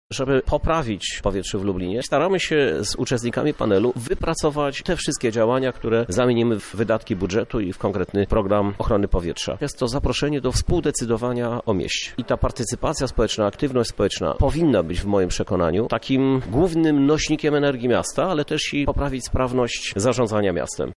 Prezydent Lublina, Krzysztof Żuk podkreśla wagę tego działania: